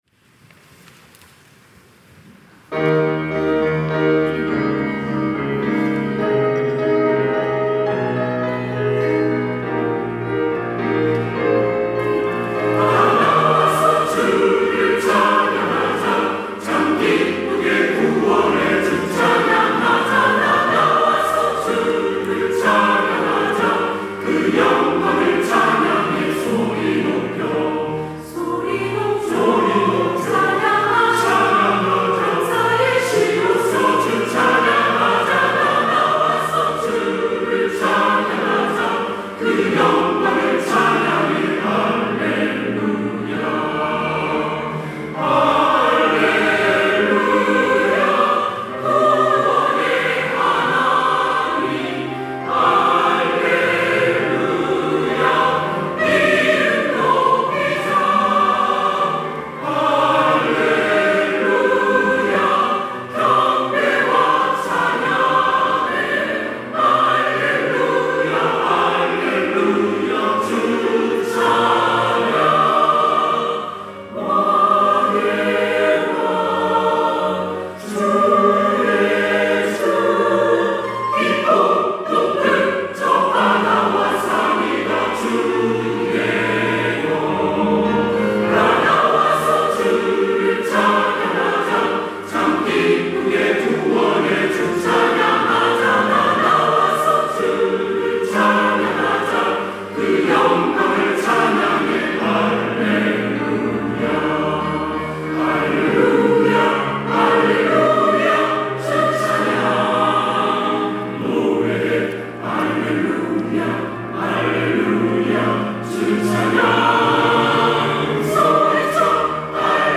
시온(주일1부) - 다 나와서 주를 찬양하자
찬양대